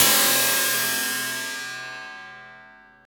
SI2 CRASH0FL.wav